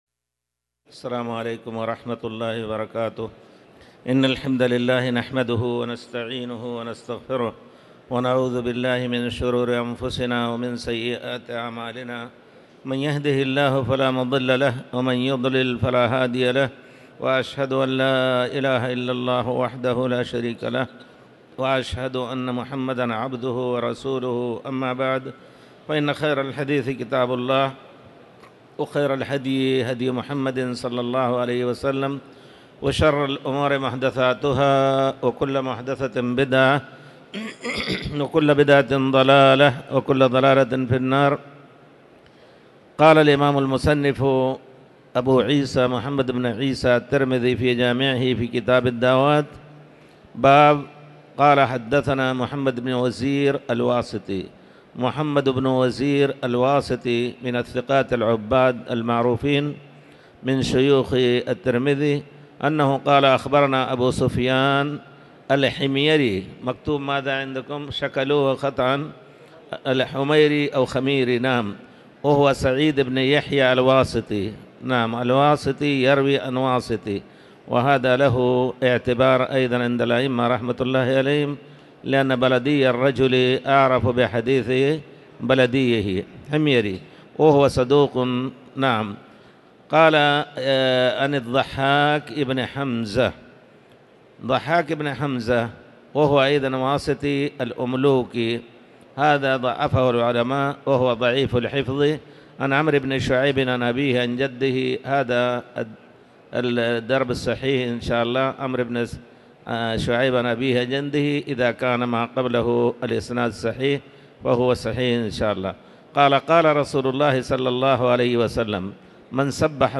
تاريخ النشر ٢٤ جمادى الأولى ١٤٤٠ هـ المكان: المسجد الحرام الشيخ